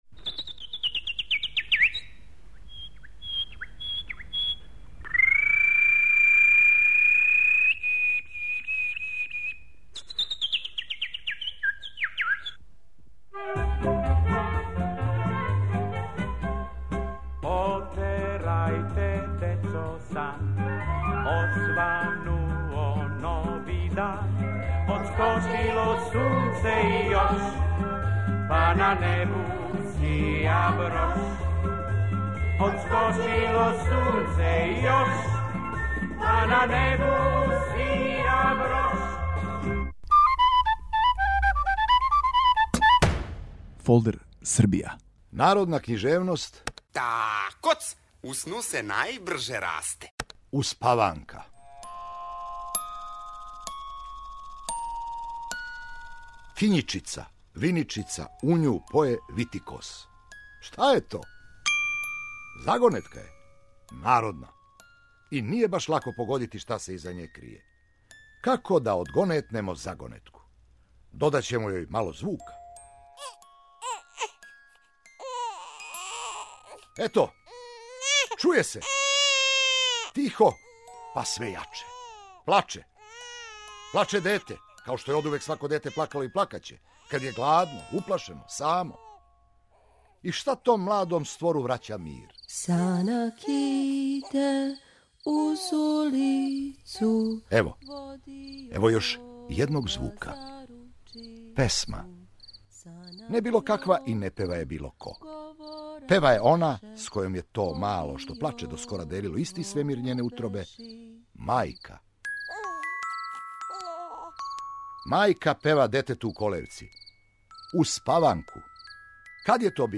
Ваш водич кроз Србију: глумац Бранимир Брстина